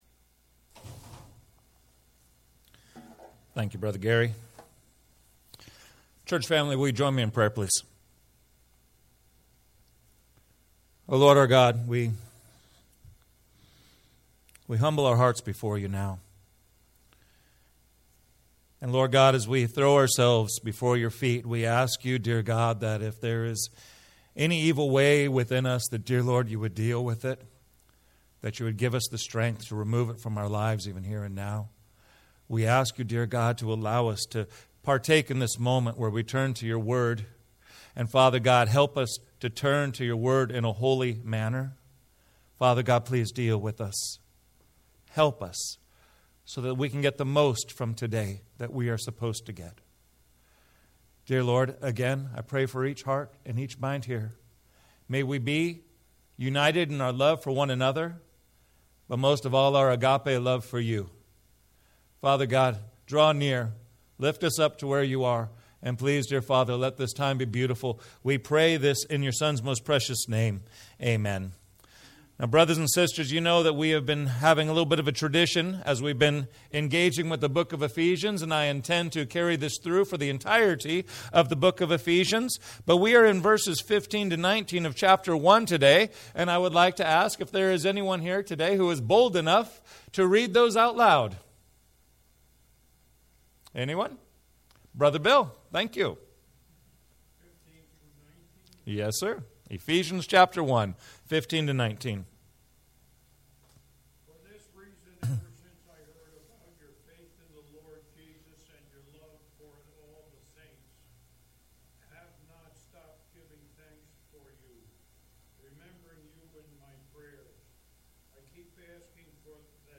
Sermons - First Baptist Church Solvang